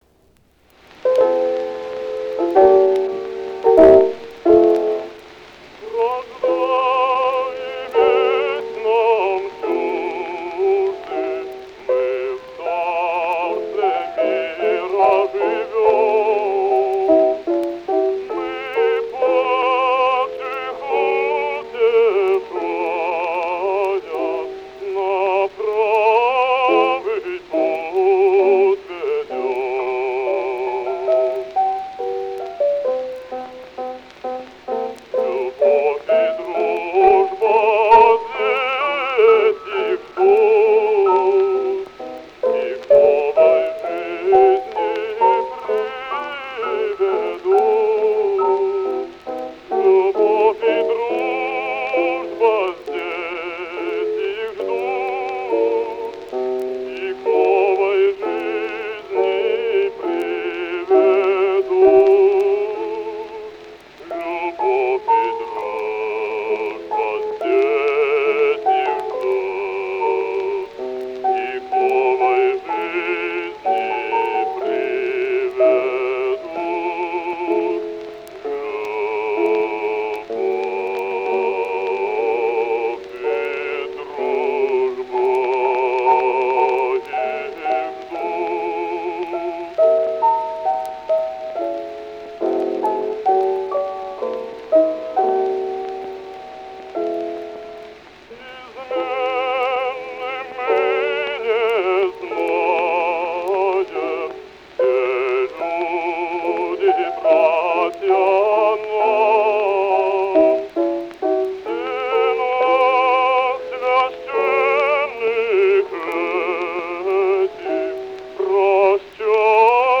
Опера «Волшебная флейта». Ария Зарастро. Исполняет В. И. Касторский.